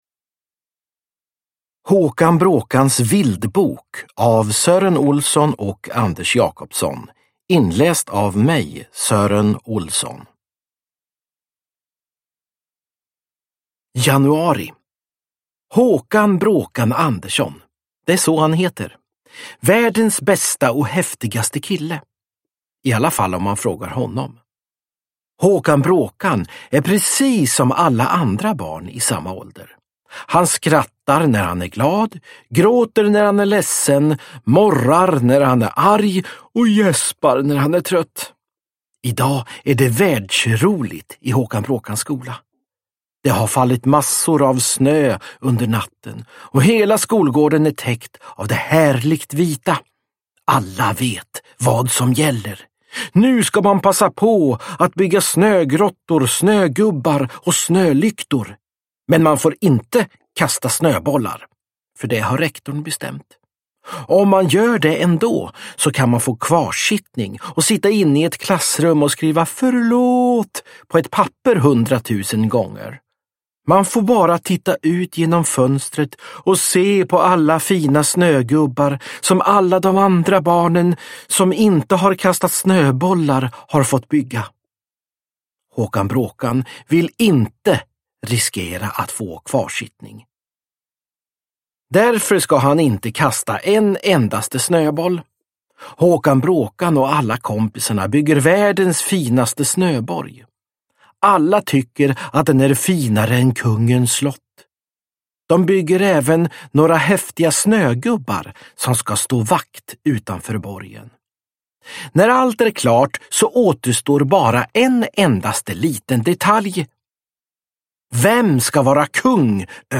Håkan Bråkans vildbok – Ljudbok – Laddas ner
Uppläsare: Sören Olsson